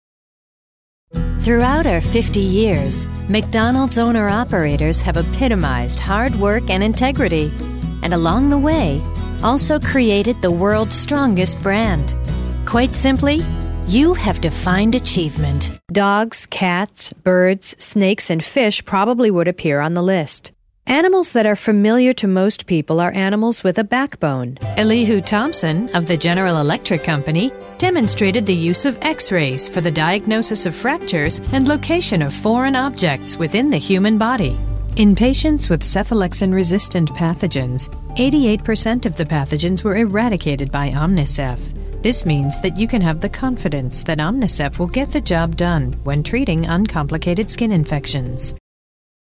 Female Voice Talent - Speedy Spots
Commercials
Warm, smooth and sultry tones that bring richness to any project
An assured, engaging narrative style that gives your voiceover the intelligent, believable sound it needs